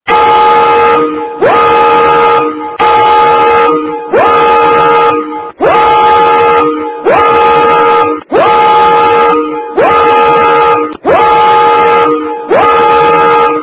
(Звук пожарной машины.